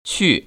[qù]